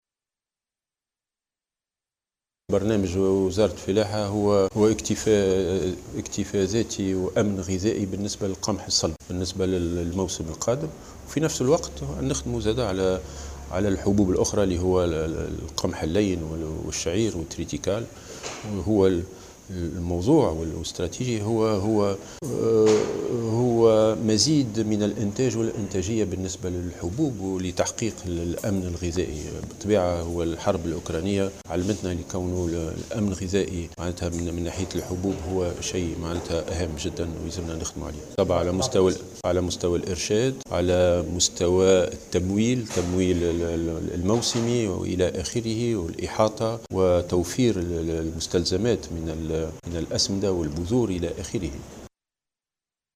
أكد وزيرُ الفلاحة محمود الياس حمزة خلال ندوة صحفية انتظمت اليوم الثلاثاء، بمقر وزارة الفلاحة حول منظومة المياه في تونس، ان برنامج الوزارة فيما يتعلق بالحبوب يتمثل في تحقيق الاكتفاء الذاتي من مادة القمح الصلب خلال الموسم القادم بالإضافة الى تسجيل كميات مهمة في انتاج بقية الحبوب.